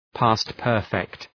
past-perfect.mp3